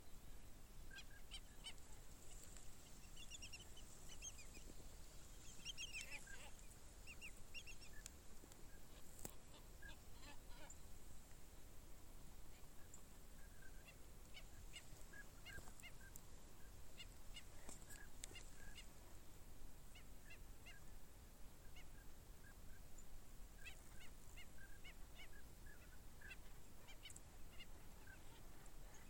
Caraúna (Plegadis chihi)
Nome em Inglês: White-faced Ibis
Localidade ou área protegida: Dique San Carlos
Condição: Selvagem
Certeza: Gravado Vocal